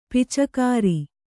♪ picakāri